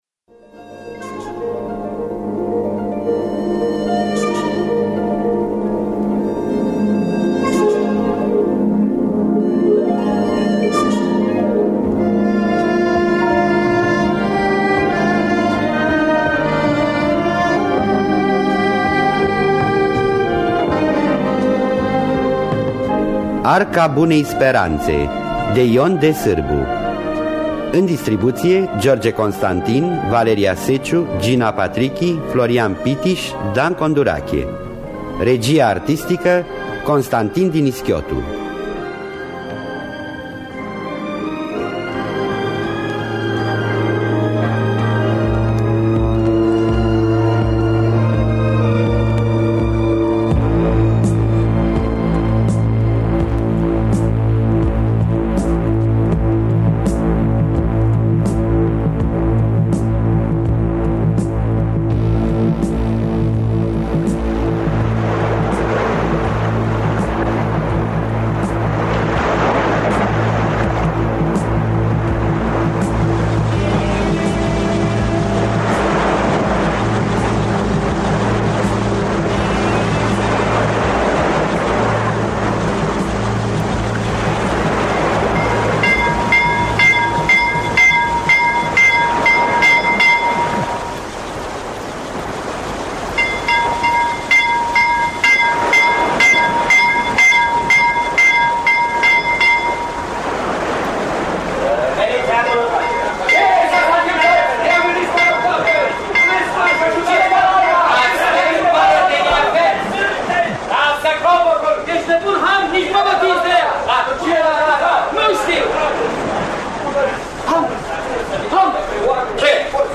Adaptarea radiofonică şi regia artistică